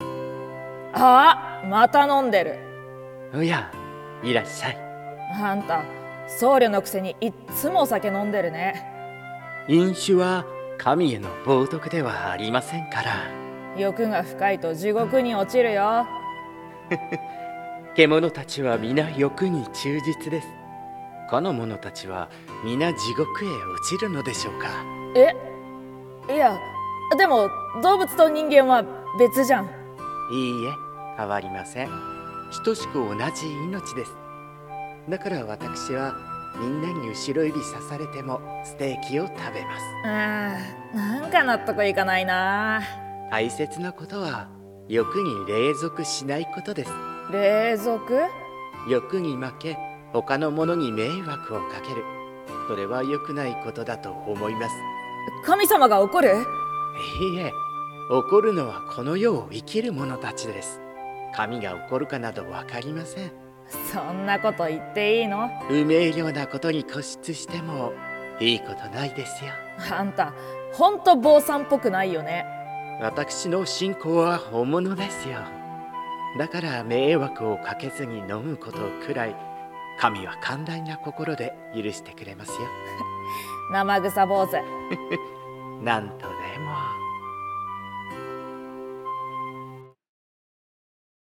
【声劇】なまぐさ